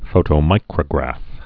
(fōtō-mīkrə-grăf)